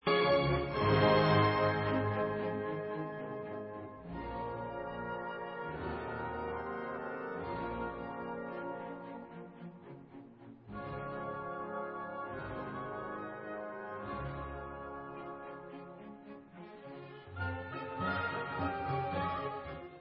b moll (Poco adagio. Vivace) /Špacírka